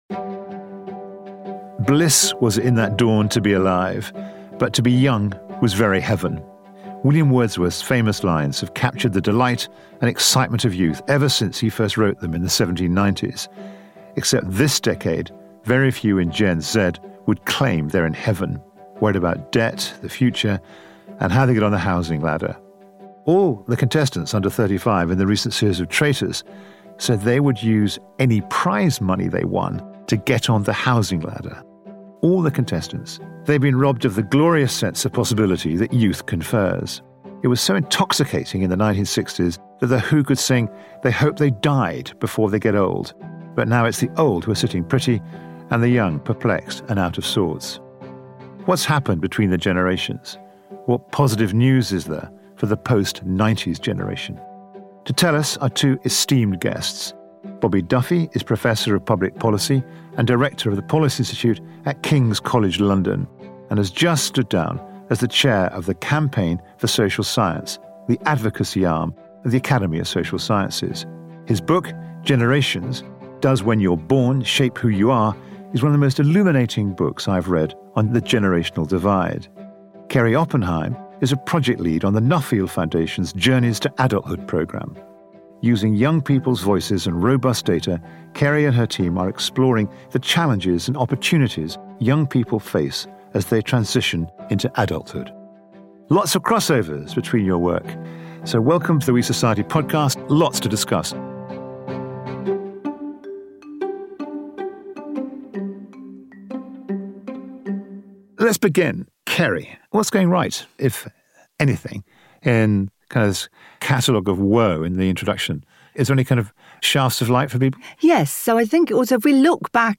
In this episode of the We Society with Will Hutton, we are joined by two esteemed academics placing journeys to adulthood under a Social Science microscope.